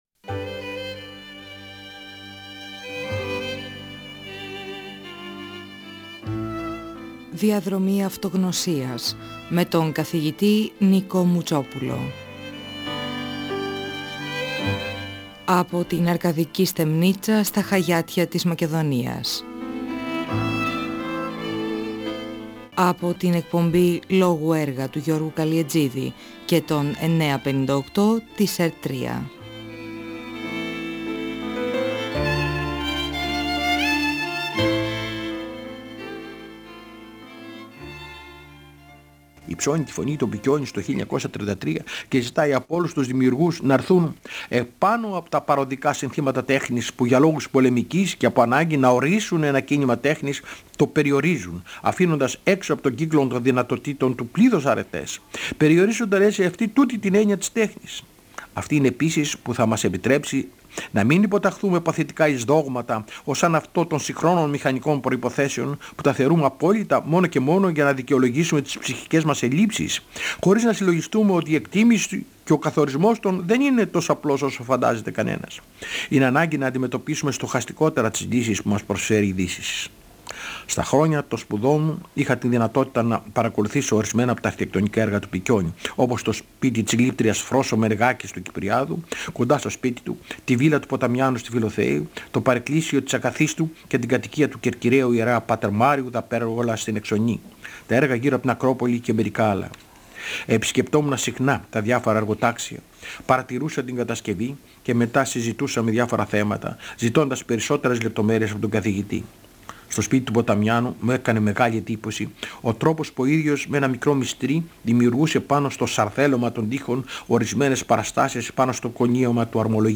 Αναφέρεται στη διαμόρφωση από τον Πικιώνη τού χώρου γύρω από την Ακρόπολη και για τον τρόπο διδασκαλίας του. Διαβάζει ένα ποίημα του Πικιώνη με τίτλο «Αγάπη».